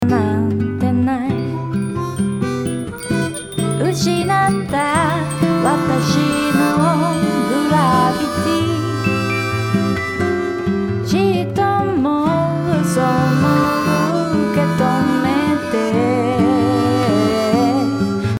ギター、ボーカル、ハーモニカの３点でミックスを進めてきましたが、そろそろすべての楽器を入れた状態でミックスを進めていきましょう。
さて、すべての楽器をある程度の音量バランスをとった後の物です。
楽器が増えると音楽から受け取るイメージが豊かになりますね。